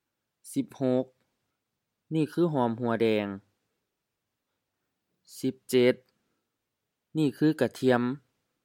หอมหัวแดง hɔ:m-hu:a-dɛ:ŋ M-M-M
กะเทียม ga-thi:am M-HR